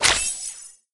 sfx_hit_ground_o.mp3